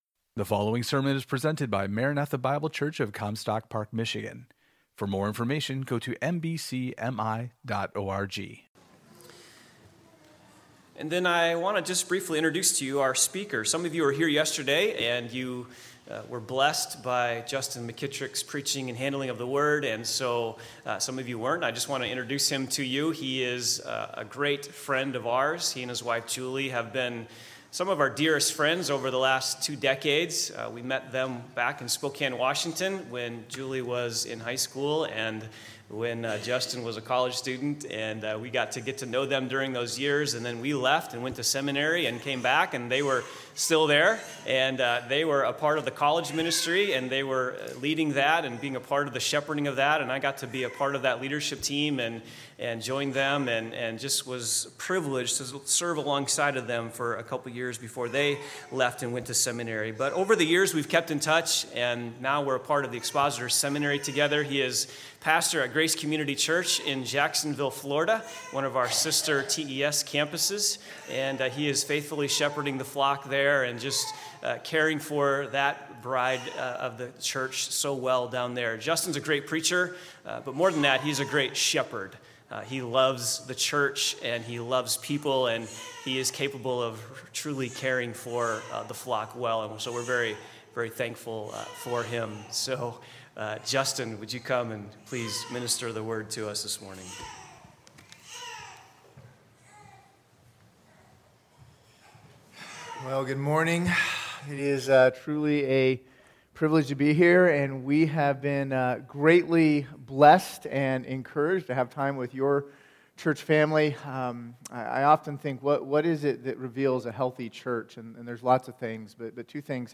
This is sermon 3 in a three part series.